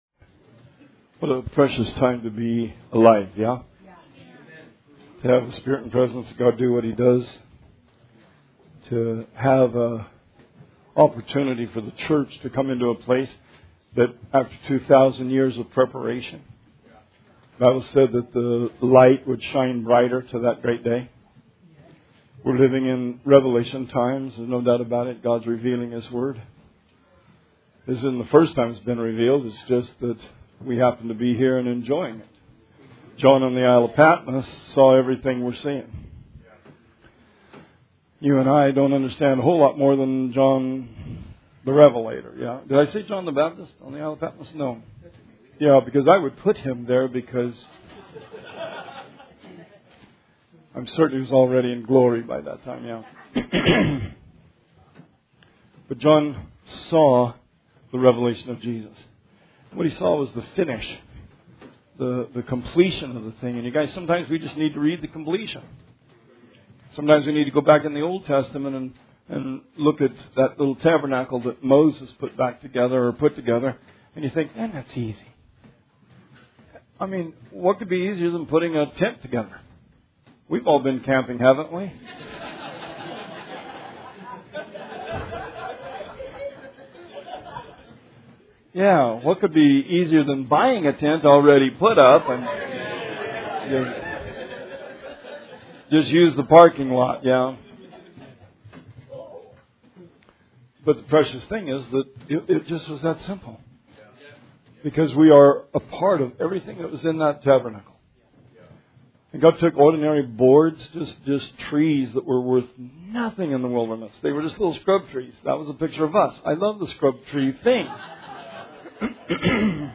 Sermon 2/16/20